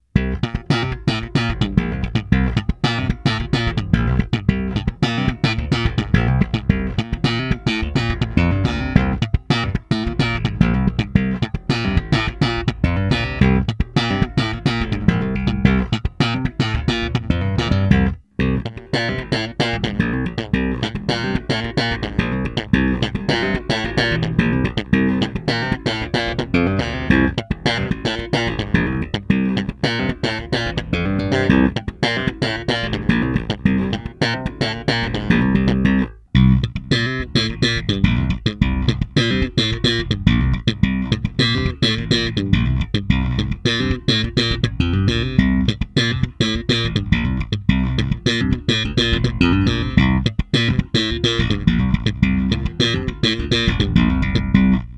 Korpus: Erle
Hals: Ahorn, einteilig, liegende Jahresringe
Tonabnehmer: 2 Singlecoil Jott-Type
Slapping (Steg-PU, Hals-PU, beide)
bb_std_slap.mp3